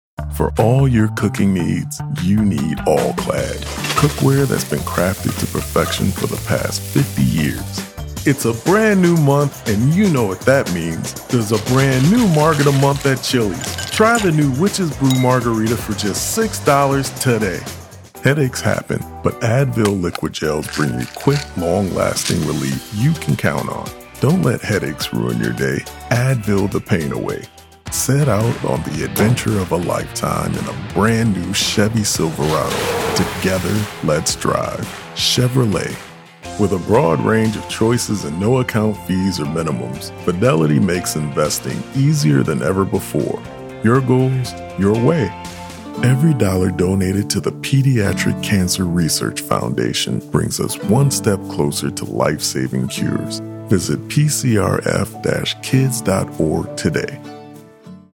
Commercial Demos
English - Midwestern U.S. English